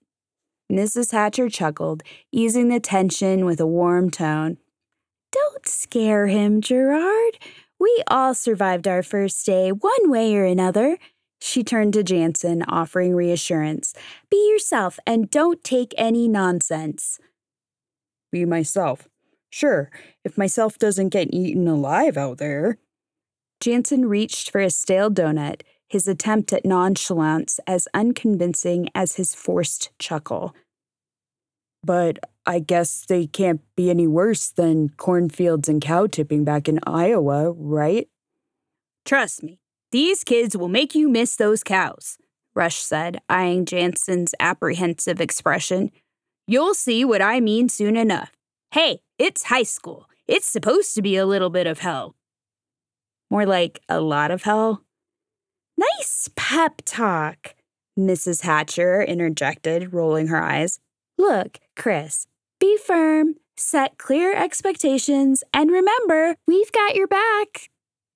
Female
I have been told I have a very youthful sounding voice.
Audiobooks
Ya Fiction – 3rd Person
Words that describe my voice are Youthful, Clear, Calm.
All our voice actors have professional broadcast quality recording studios.